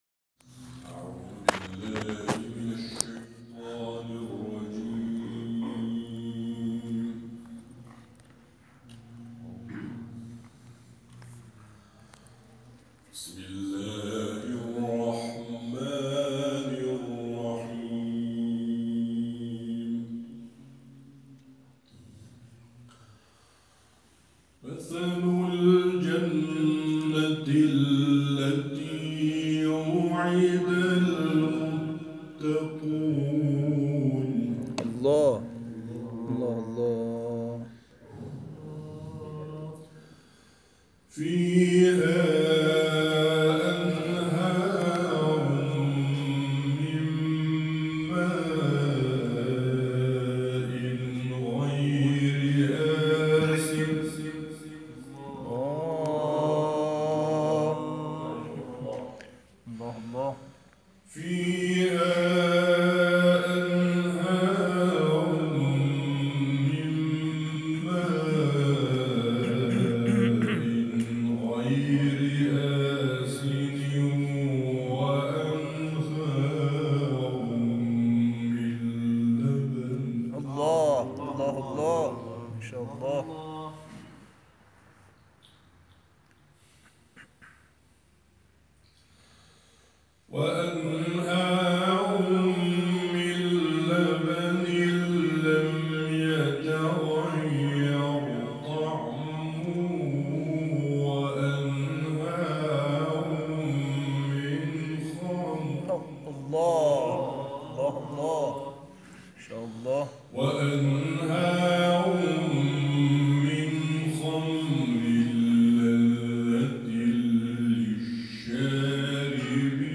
تلاوت در کانال‌های قرآنی/
این تلاوت کوتاه روز گذشته در جلسات هفتگی مؤسسه حدید اجرا شده است.